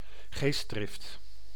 Ääntäminen
IPA: /zɛl/